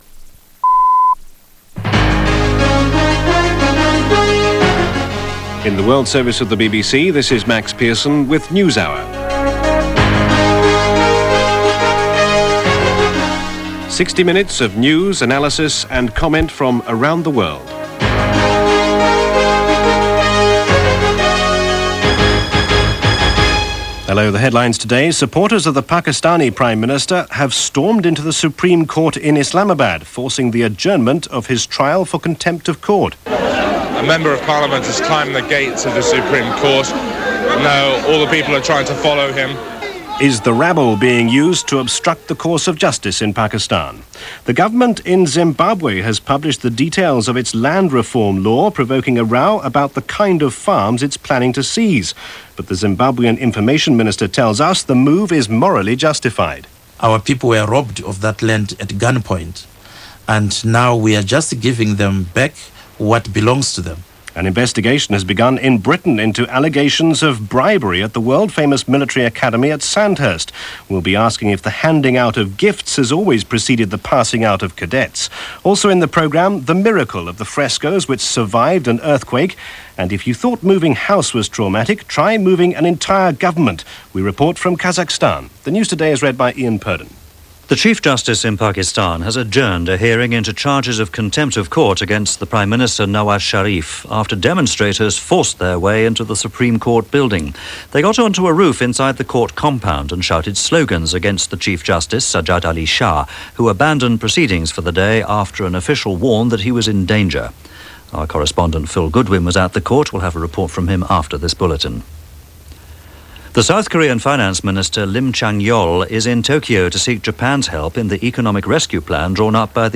November 28, 1997 - Pakistan: Fying Fists - Zimbabwe: Old Fashioined Land Reform - news of this day in 1997 - BBC World Service.